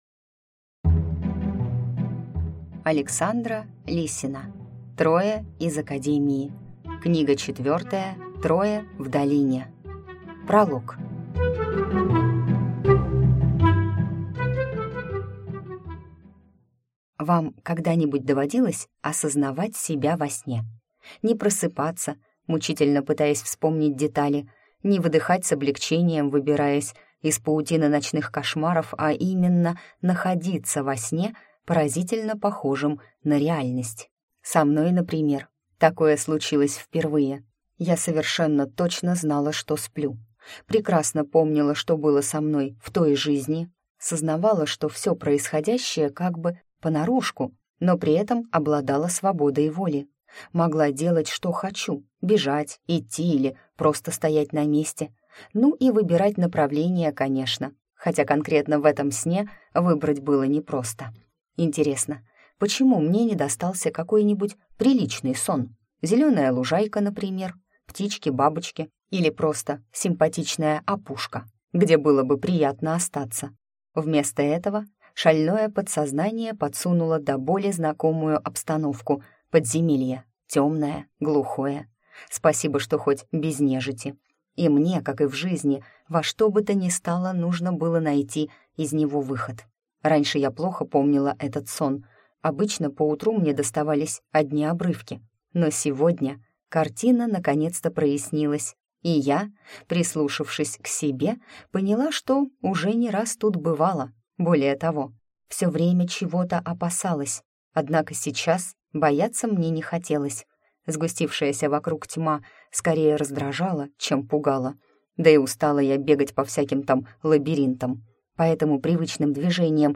Аудиокнига Трое в долине | Библиотека аудиокниг